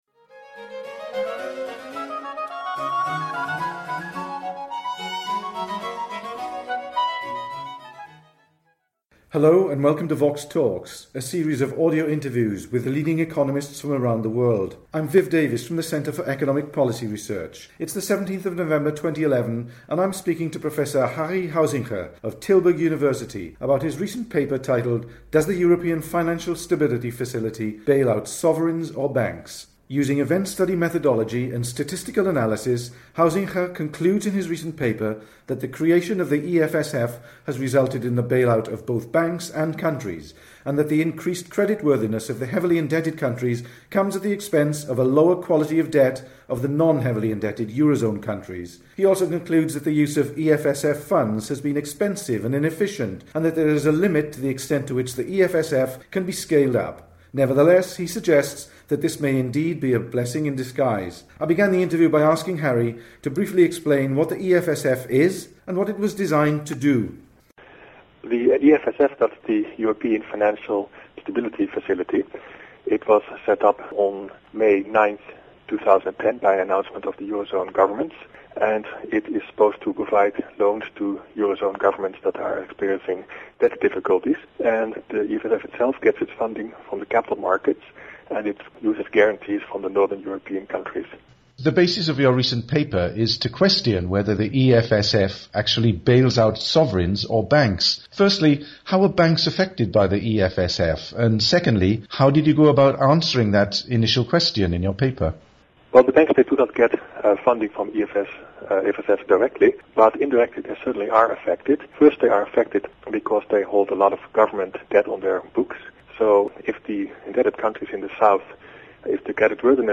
The interview was recorded on 17 November 2012.